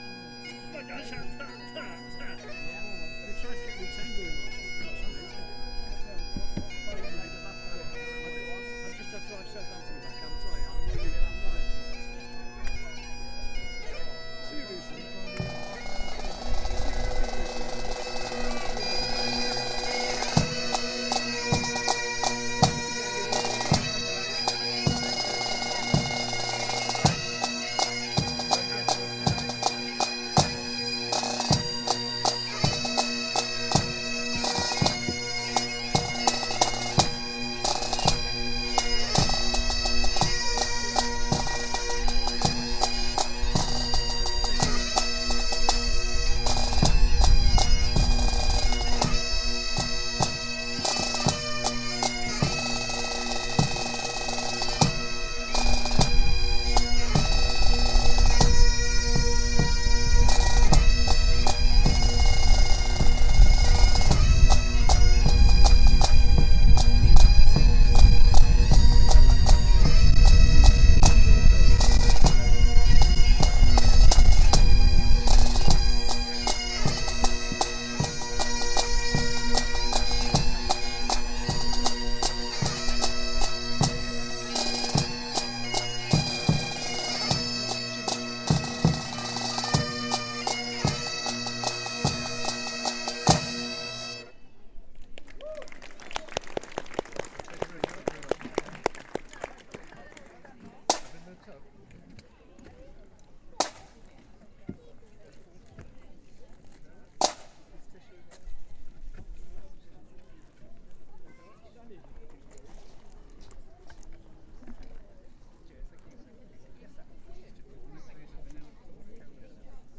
Pipe Band Boo 3, including some dodgy tuning and funny conversation snippet
St Andrew's Pipe Band from Cheltenham in Priory Park, Malvern, on Sunday